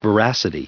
added pronounciation and merriam webster audio
730_veracity.ogg